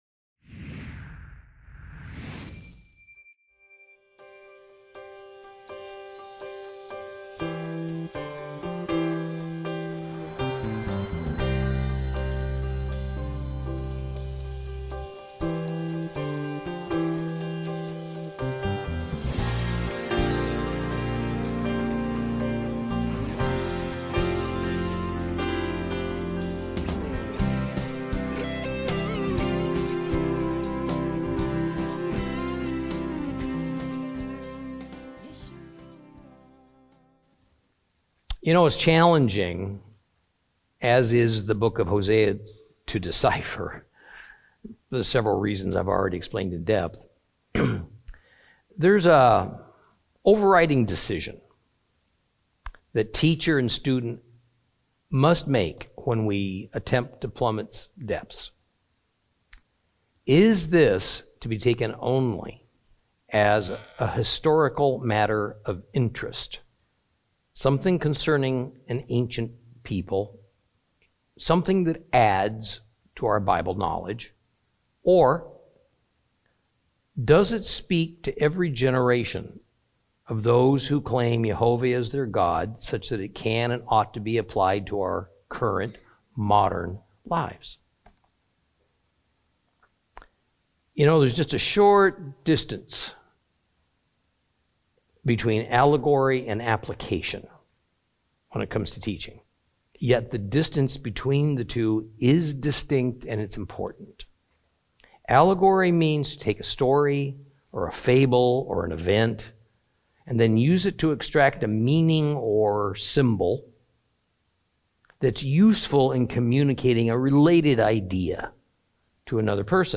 Teaching from the book of Hosea, Lesson 18 Chapter 10 continued.